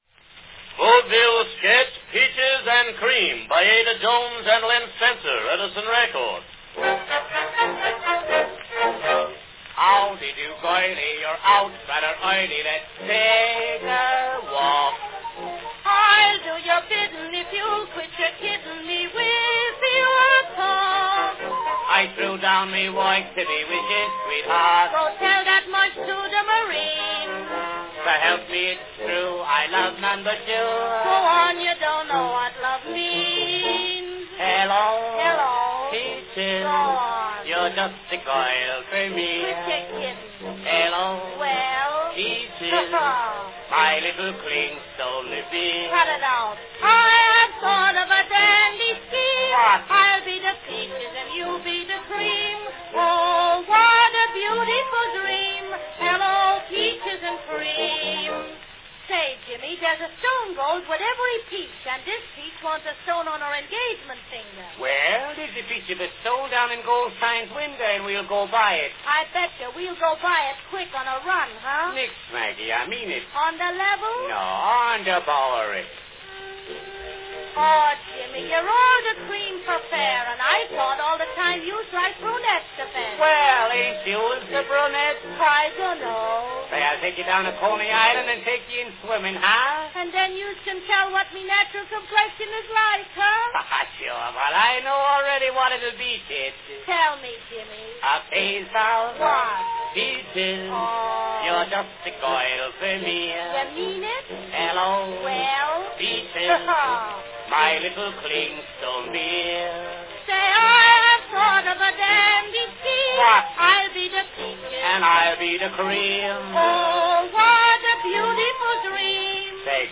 Category Vaudeville sketch
with orchestral incidental effects